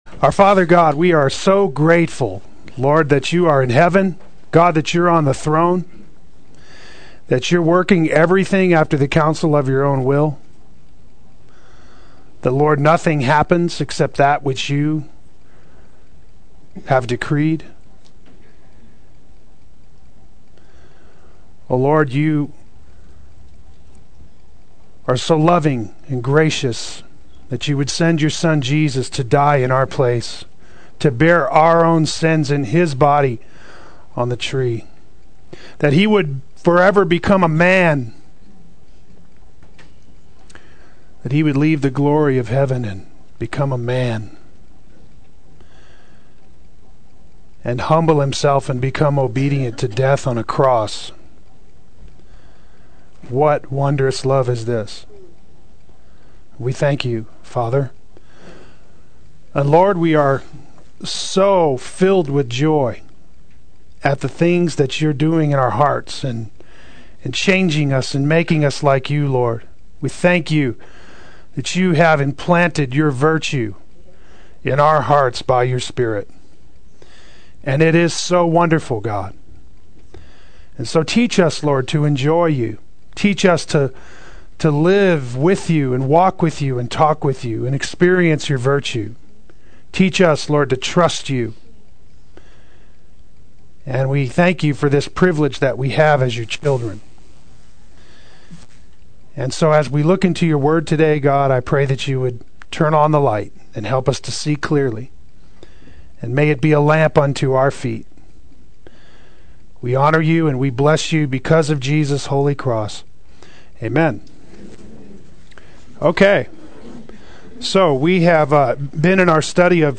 Regular Fellowship With Godly Christians Adult Sunday School